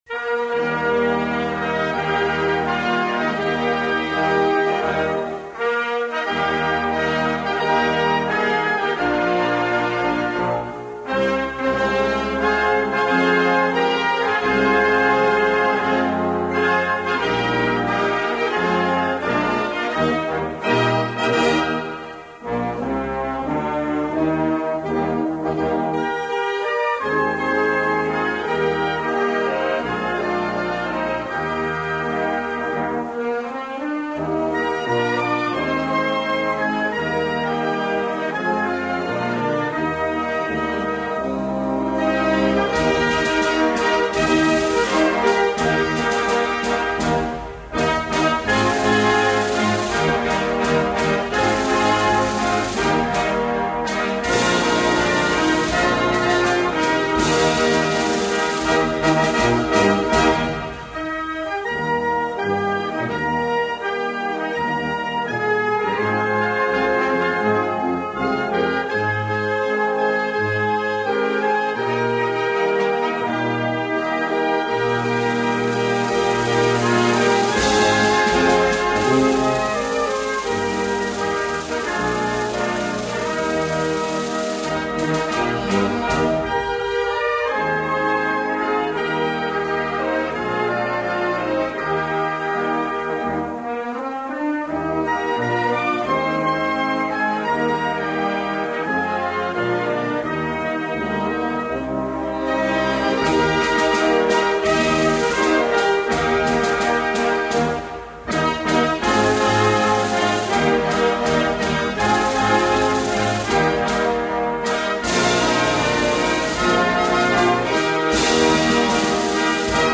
Independência_total_(instrumental).mp3